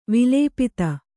♪ vilēpita